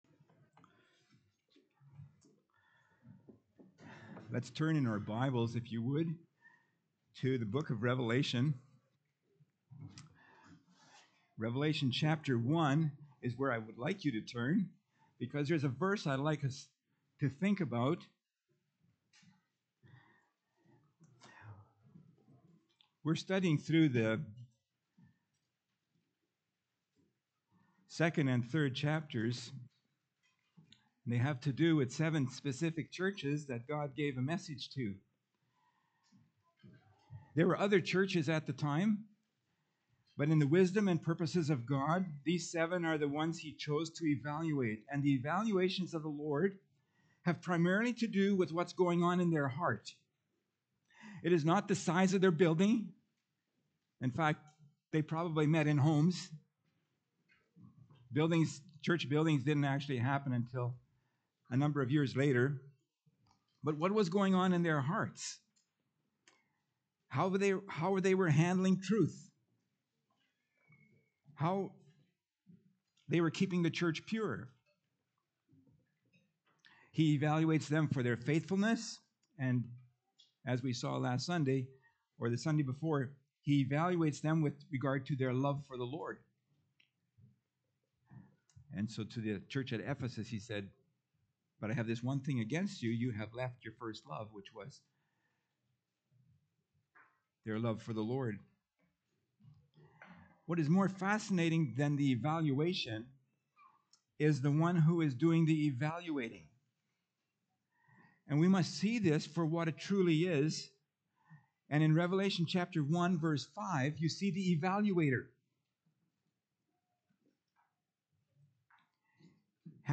Pulpit Sermons Key Passage: Revalation 2:8-11 https